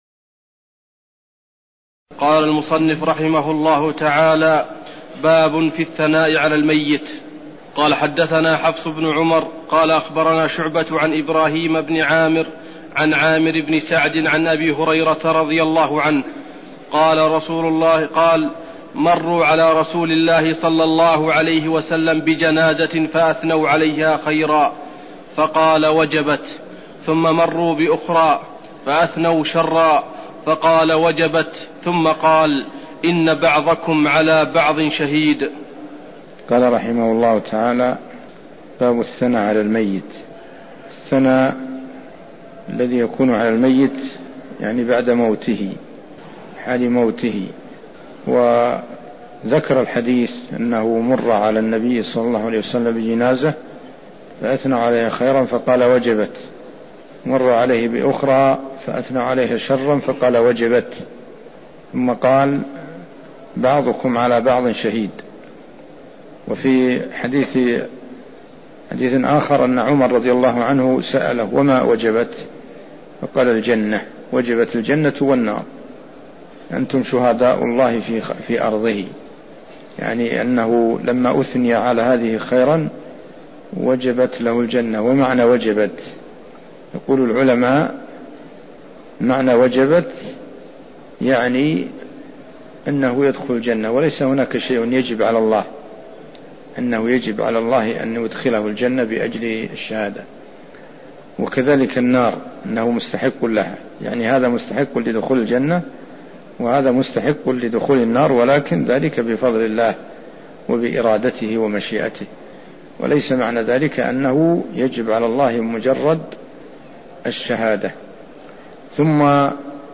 المكان: المسجد النبوي الشيخ